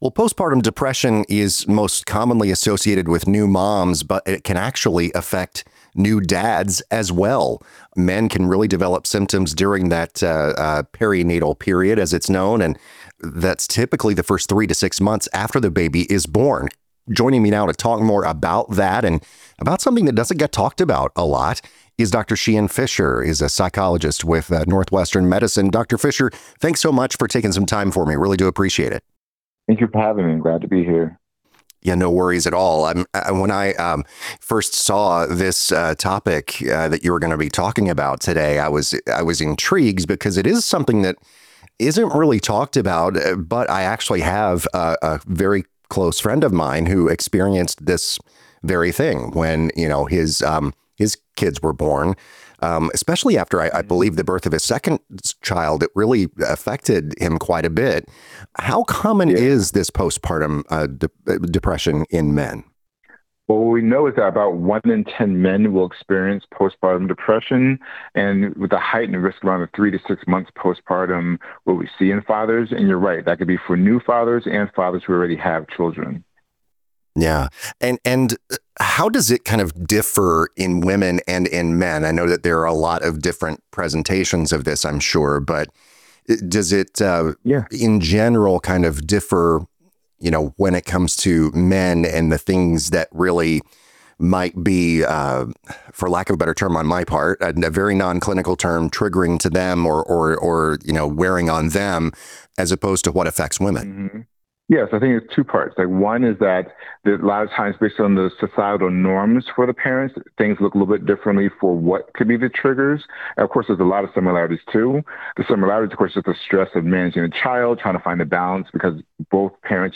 In our conversation, we dive into: